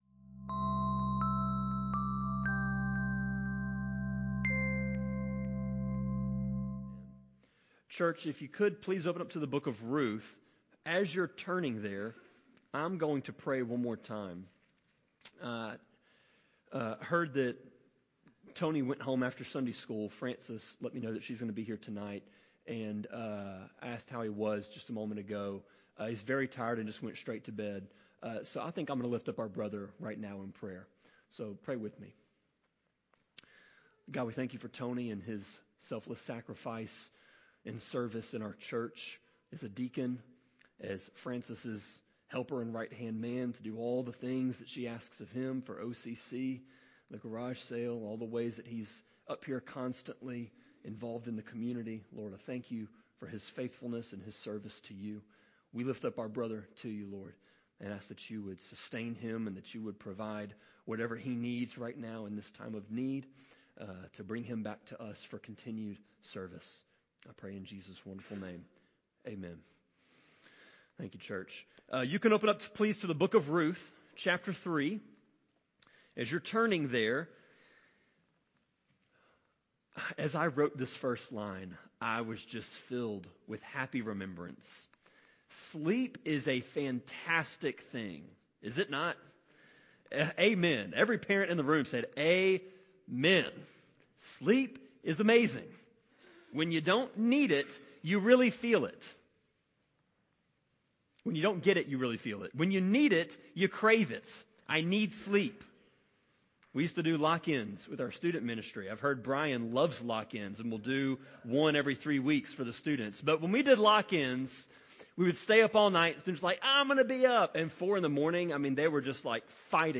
Sermon-26.3.29-CD.mp3